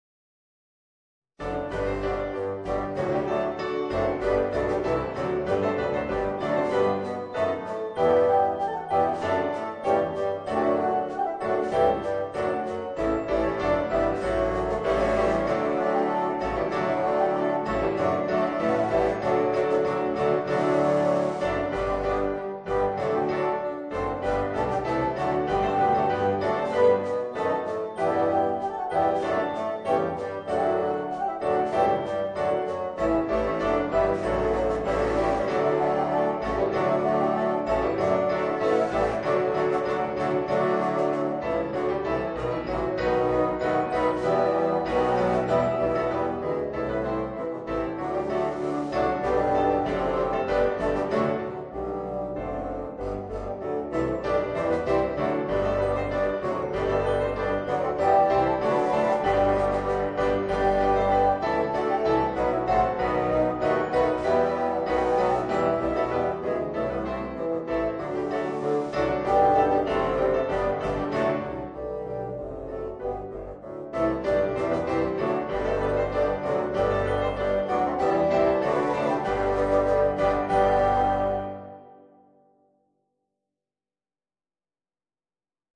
Voicing: 8 Bassoons